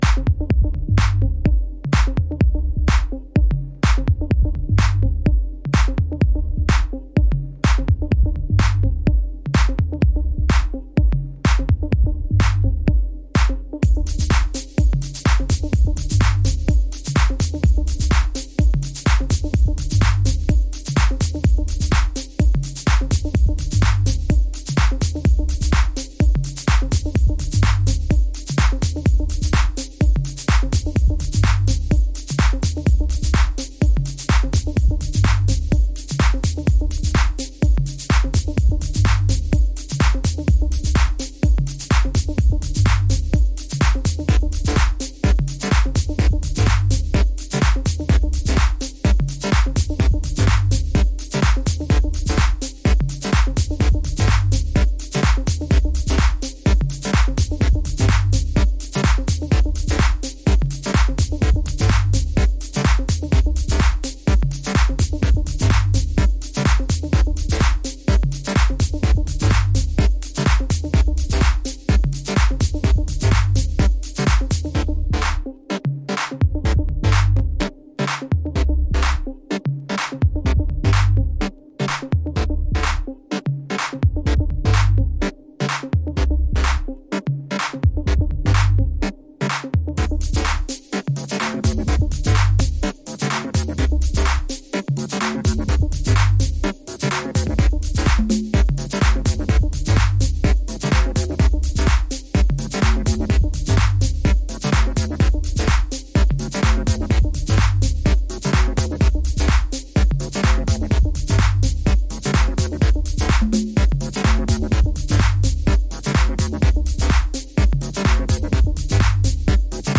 House / Techno
ダブテクノ流儀のシンプルなビートとシンセの起伏が心地良いディープテクノ。粒子の粗さも癖になりますね。